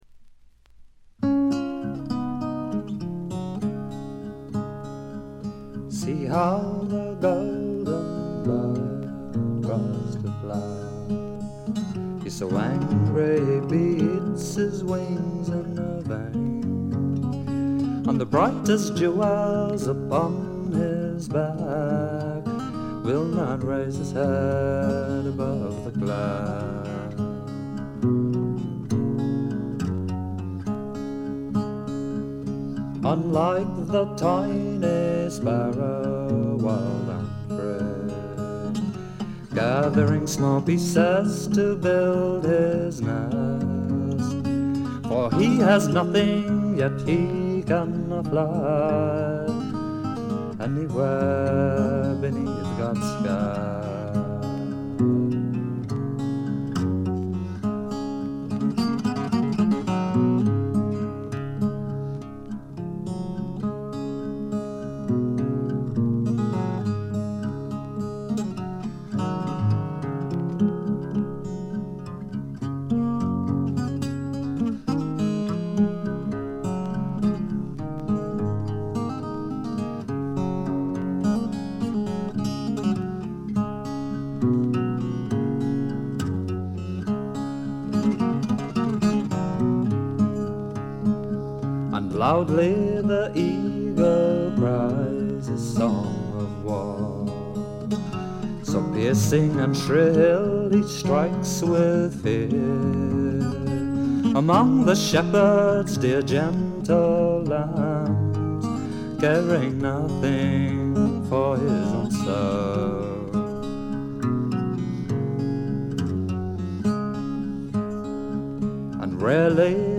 軽微なバックグラウンドノイズ、チリプチ少し。
ゲストミュージシャンは一切使わずに、自作とトラッドを味のあるヴォーカルと素晴らしいギターで表情豊かに聴かせてくれます。
試聴曲は現品からの取り込み音源です。